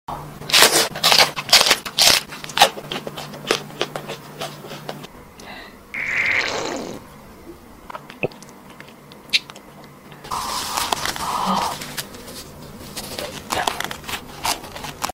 Random Color food mukbang Korean sound effects free download
Random Color food mukbang Korean ASMR Testing